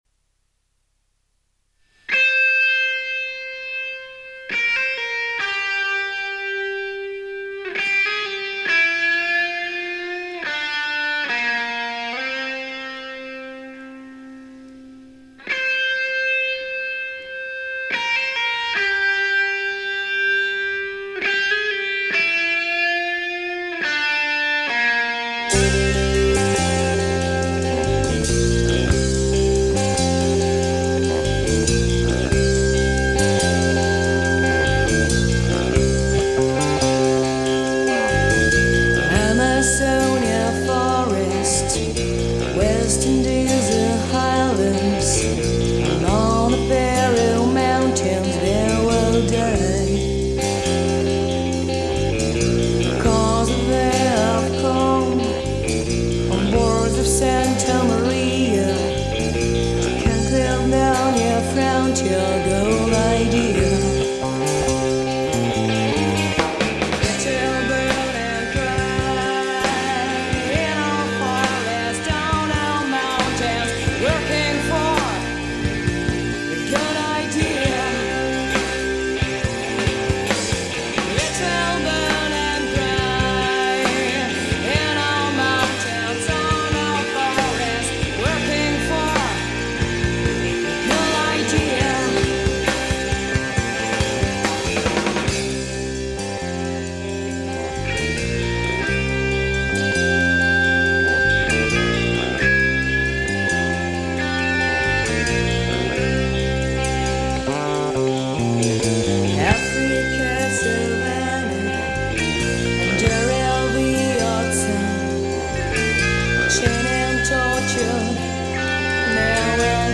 guitare
basse
batterie
Studio Mirabeau Marseille Sep. 93